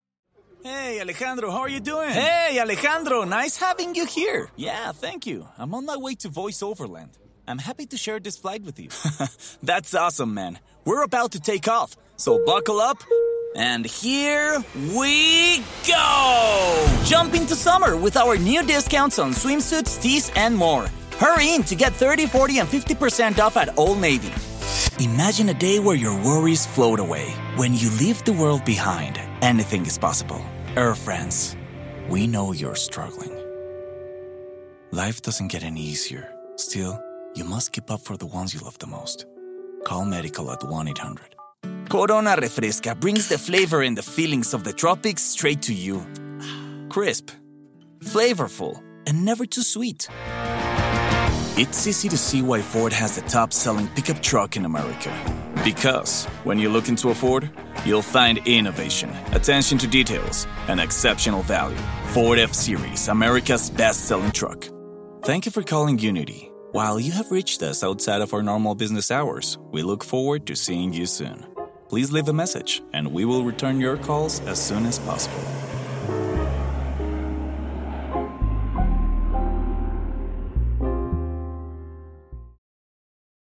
Kommerzielle Demo
Mikrofone: Neumann TLM 103 (Haupt), Telefunken TF51 (Backup), Audio Technica 2035 (Backup).